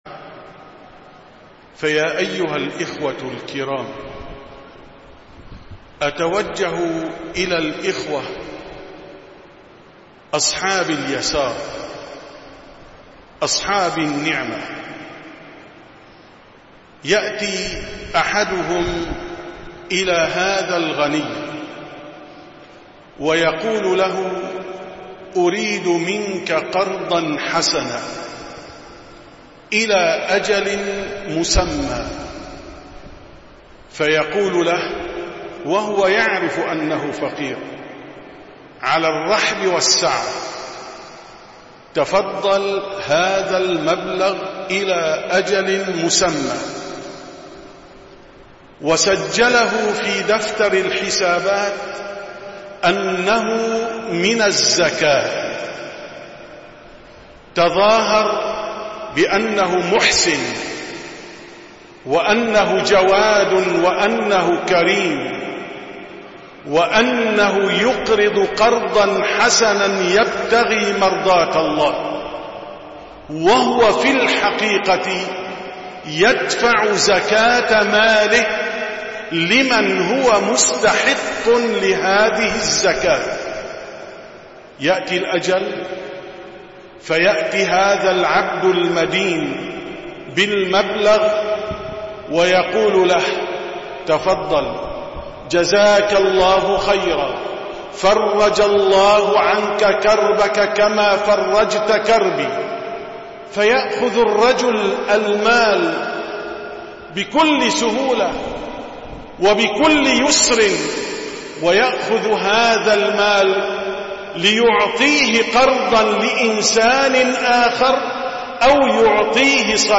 19- كلمة مختصرة في صلاة التراويح 1440هــ: حكم الزكاة المستردة
دروس رمضانية